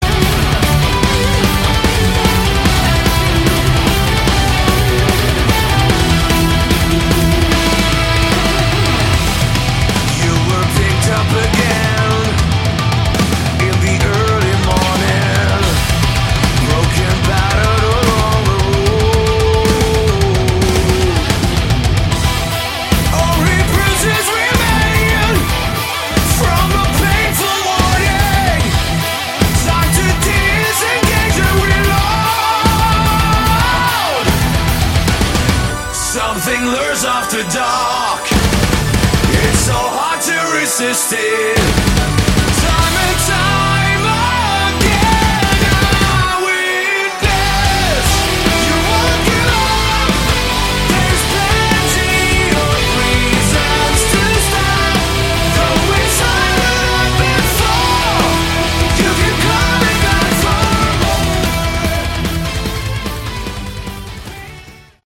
Category: Melodic Metal
vocals
guitars, bass
keyboards
drums